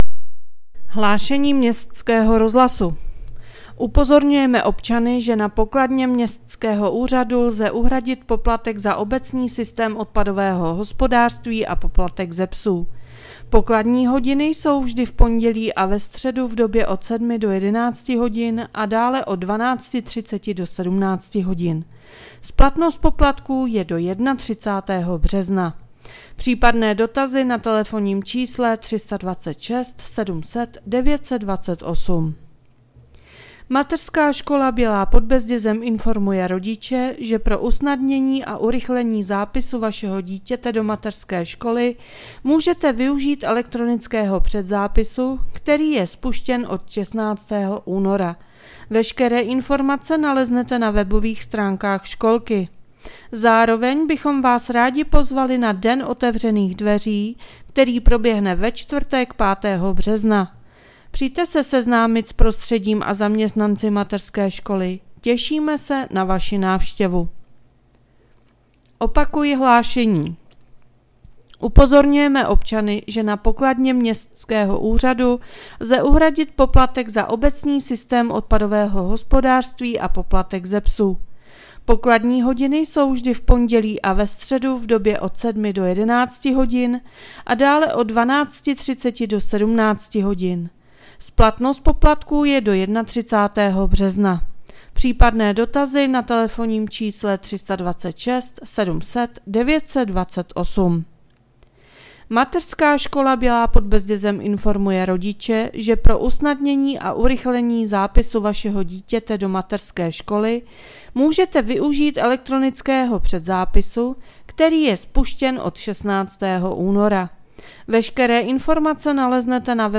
Hlášení městského rozhlasu 4.3.2026